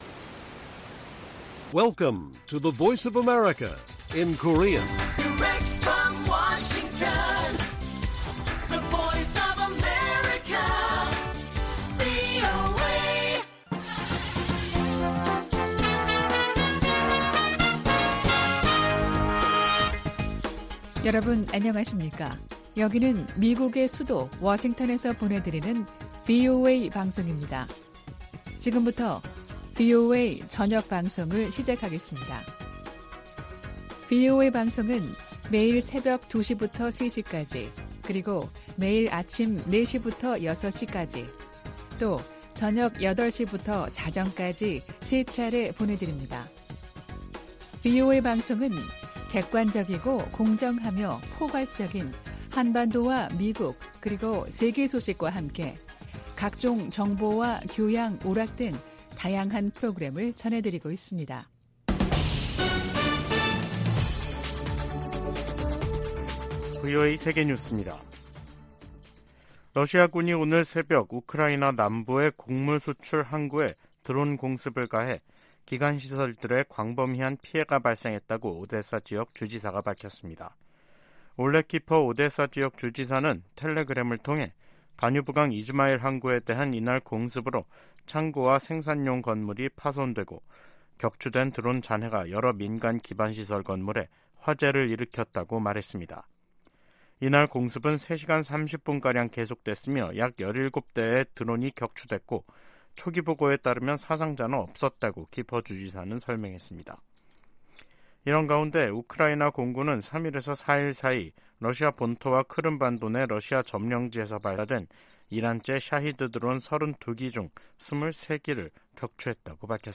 VOA 한국어 간판 뉴스 프로그램 '뉴스 투데이', 2023년 9월 4일 1부 방송입니다. 북한이 전략순항미사일을 발사하며 핵 공격 능력을 과시하려는 도발을 이어갔습니다. 러시아가 북한에 북중러 연합훈련을 공식 제의했다고 한국 국가정보원이 밝혔습니다. 백악관은 바이든 행정부가 인도태평양 지역을 중시하고 있다고 거듭 강조했습니다.